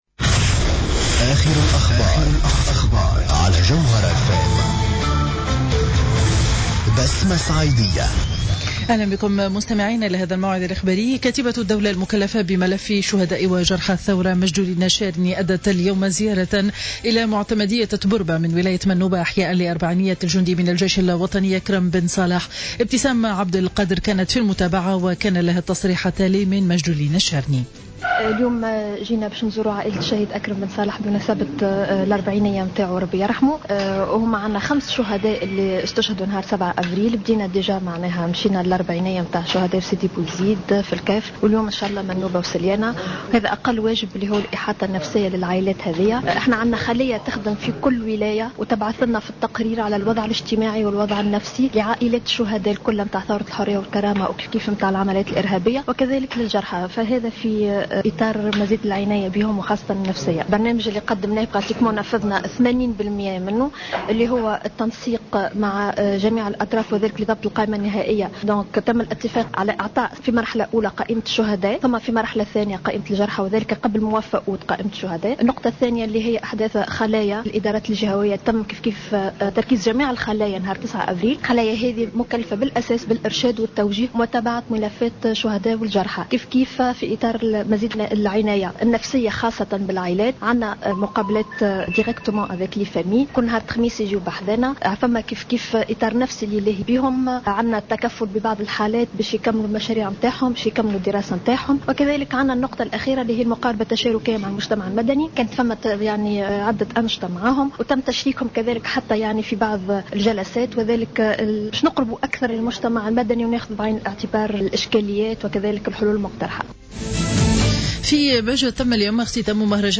نشرة أخبار منتصف النهار ليوم الأحد 17 ماي 2015